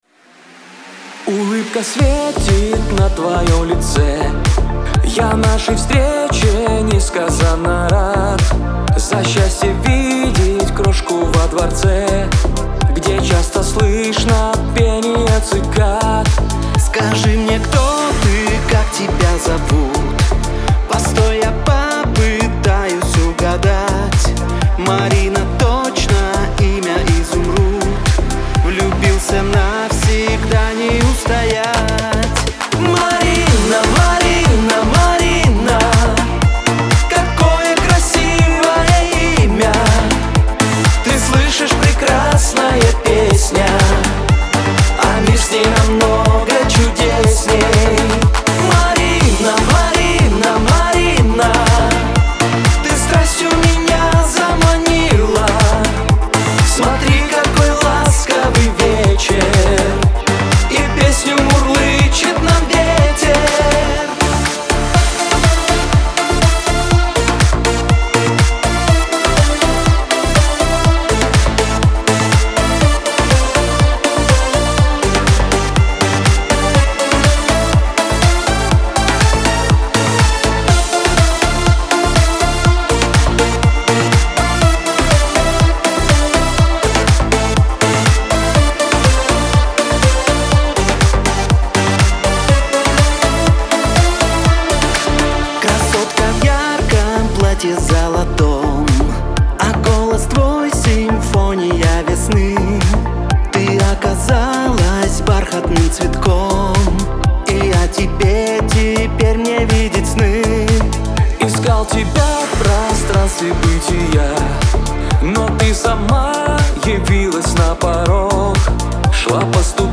диско , ансамбль
pop
эстрада